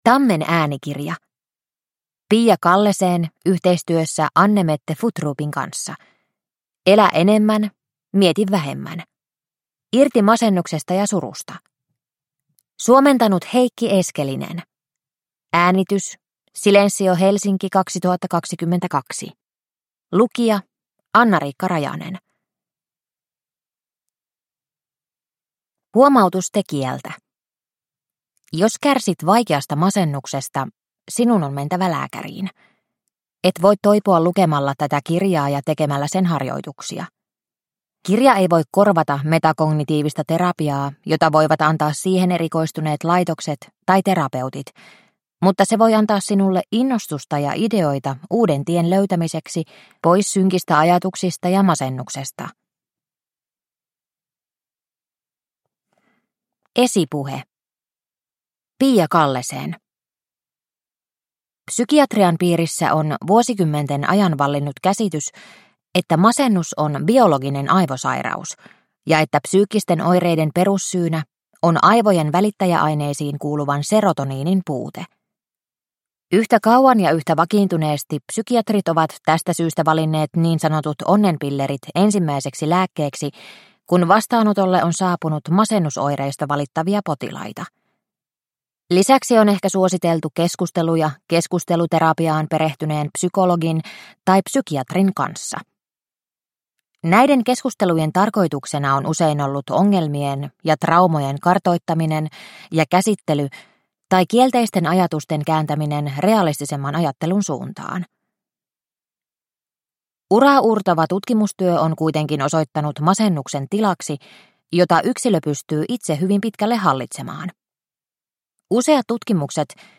Elä enemmän, mieti vähemmän – Ljudbok – Laddas ner